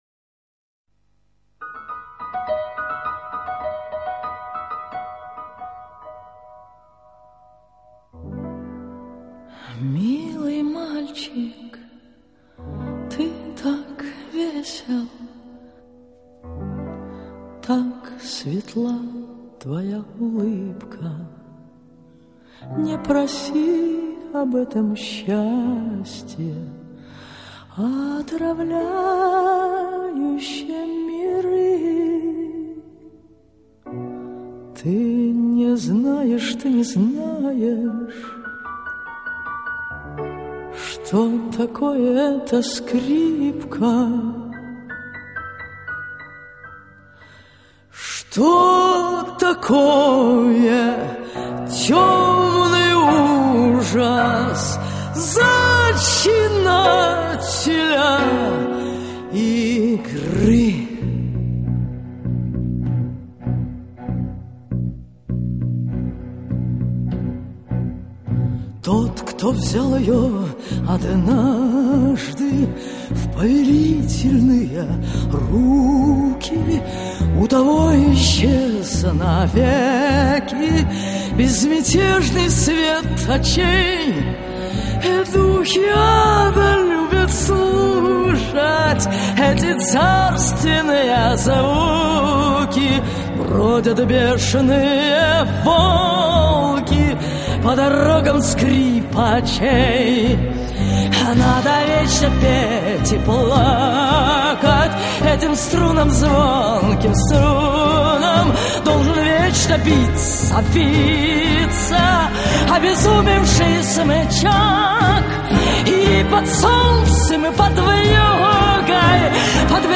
Стихи и музыка к стихам.